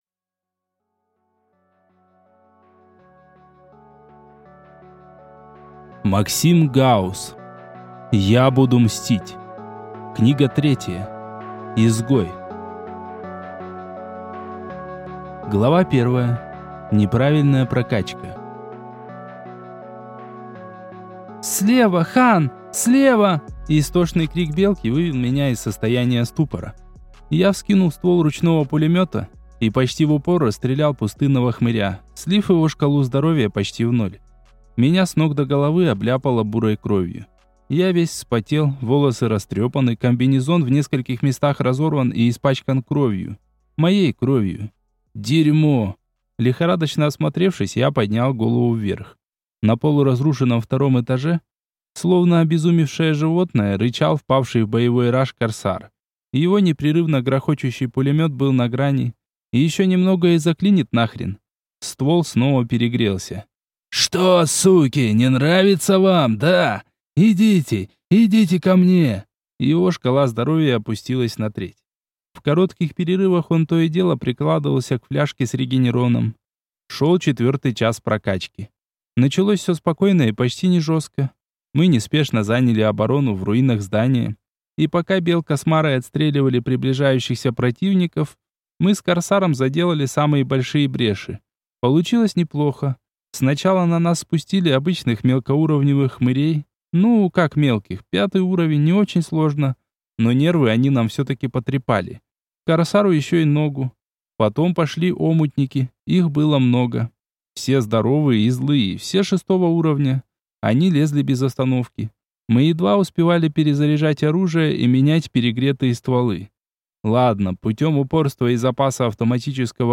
Аудиокнига Я буду мстить. Книга 3. Изгой | Библиотека аудиокниг